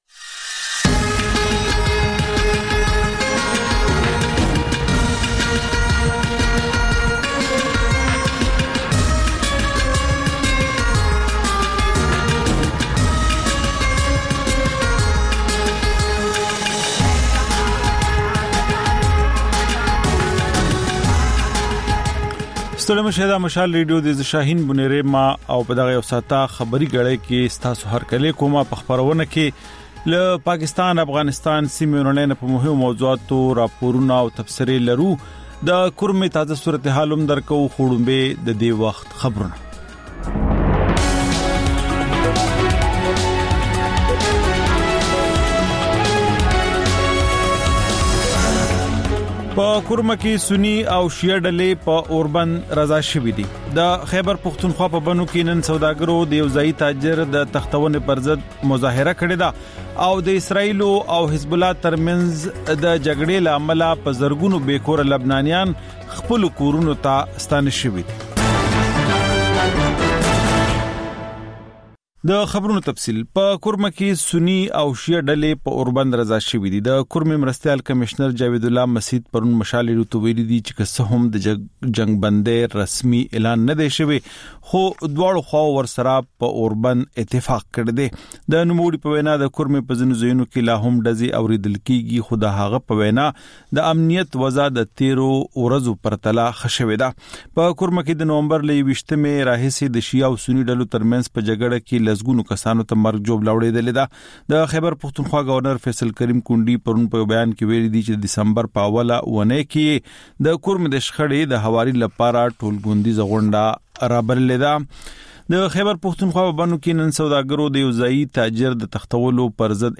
د مشال راډیو د ۱۴ ساعته خپرونو دویمه او وروستۍ خبري ګړۍ. په دې خپرونه کې تر خبرونو وروسته بېلا بېل سیمه ییز او نړیوال رپورټونه، شننې، مرکې، کلتوري او ټولنیز رپورټونه خپرېږي.